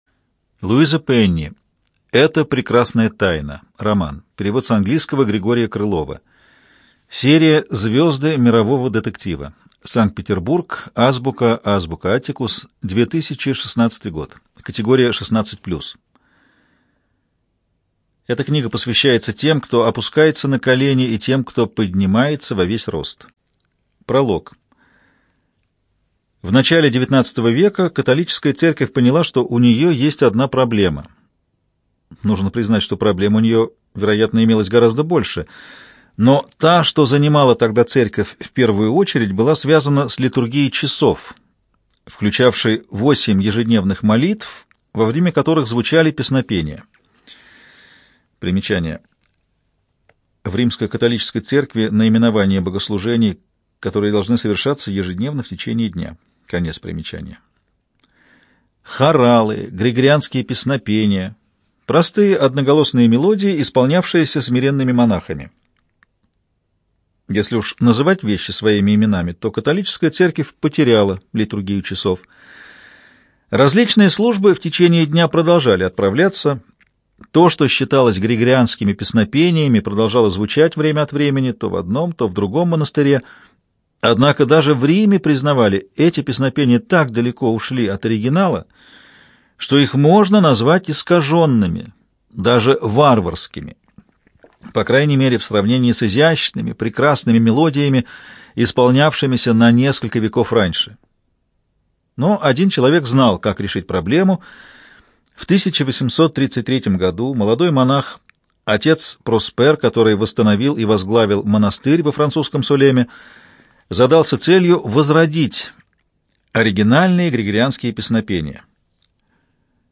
ЖанрДетективы и триллеры
Студия звукозаписиЛогосвос